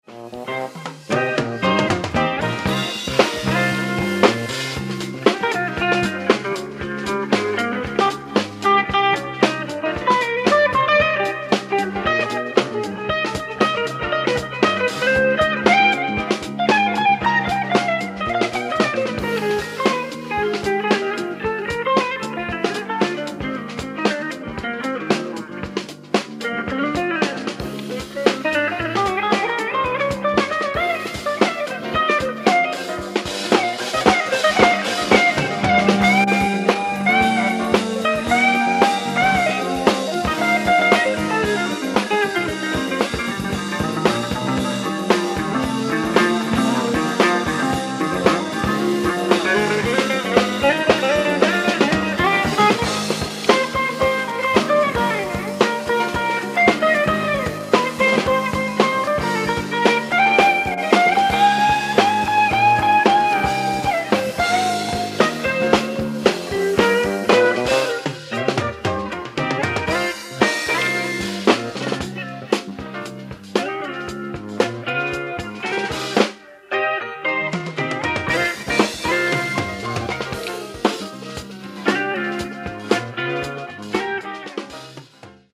ライブ・アット・バンキーズ・タバーン、マディソン、ウィスコンシン 05/28/1978
※試聴用に実際より音質を落としています。